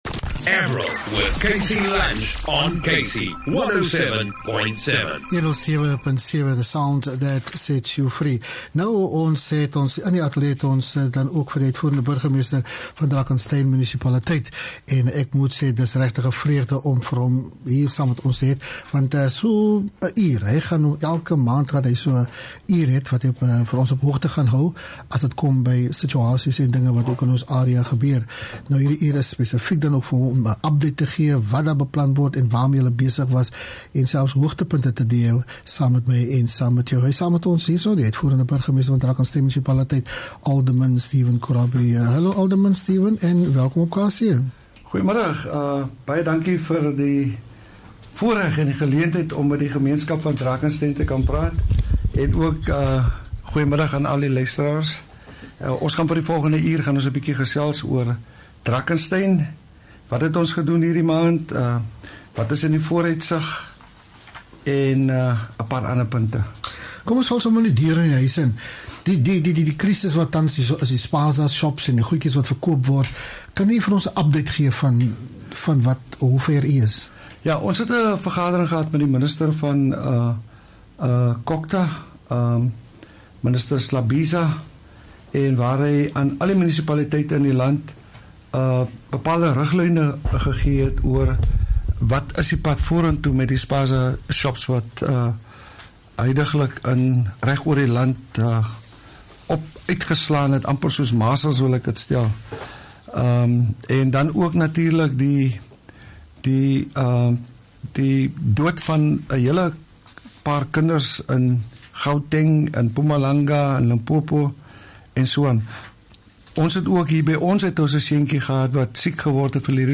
ON KC LUNCH, EXECUTIVE MAYOR OF DRAKENSTEIN MUNICIPALITY, ALDERMAN STEPHEN KORABIE GIVES AN UPDATE TO THE COMMUNITY ABOUT DIFFERENT ISSUES.